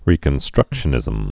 (rēkən-strŭkshə-nĭzəm)